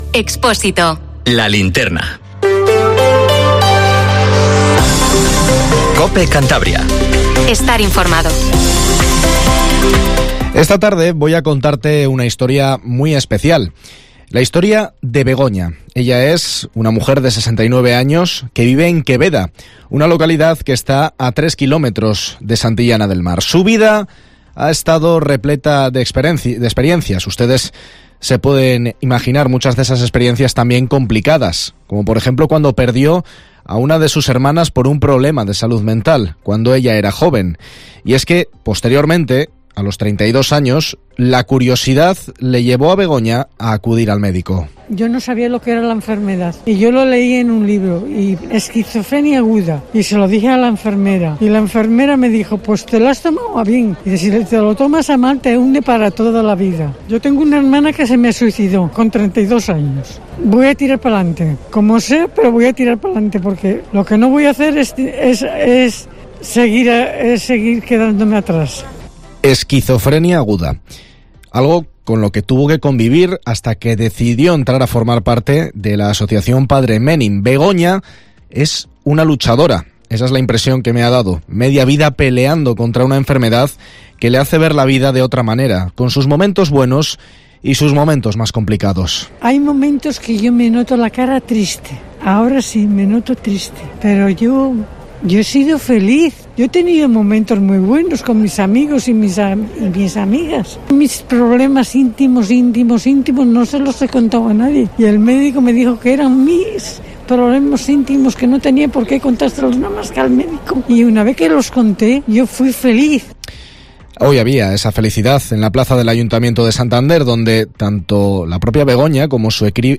Informativo LA LINTERNA en COPE CANTABRIA 19:50